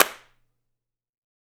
West Metro Clap (4).wav